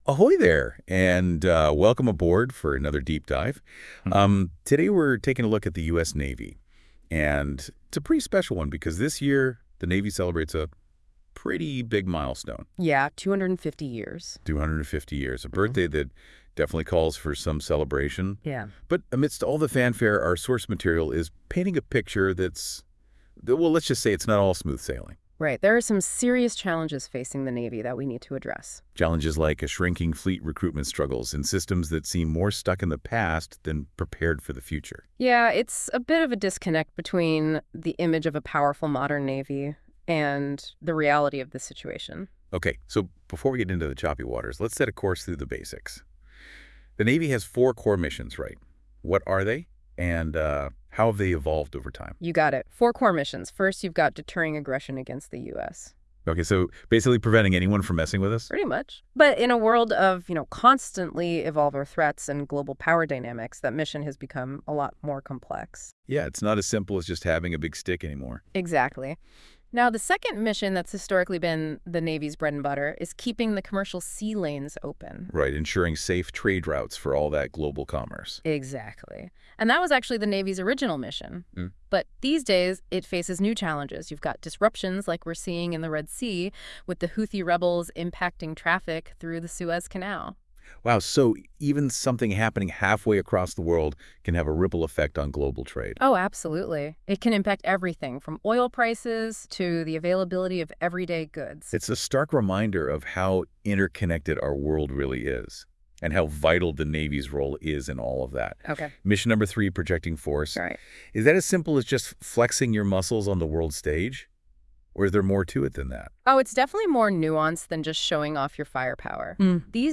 Can Trump Turn The U.S. Navy Around? If anyone can... Listen to two A.I.'s discuss it, plus details below - World News